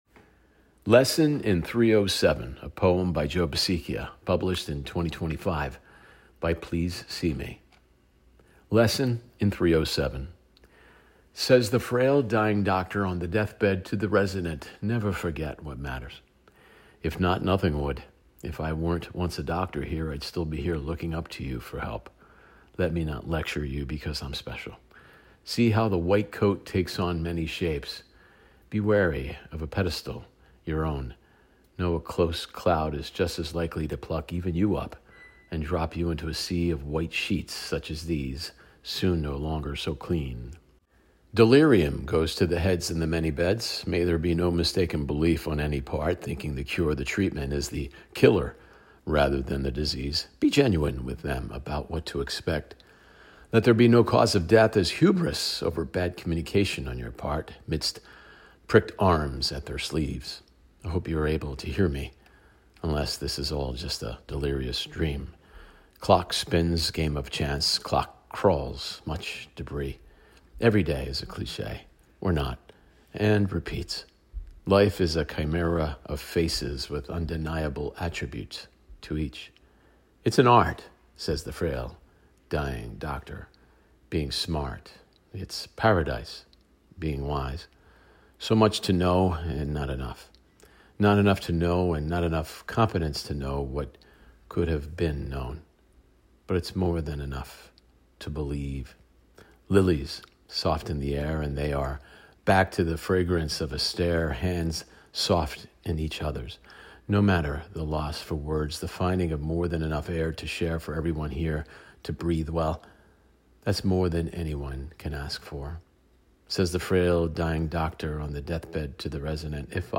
reads “Lesson in 307”: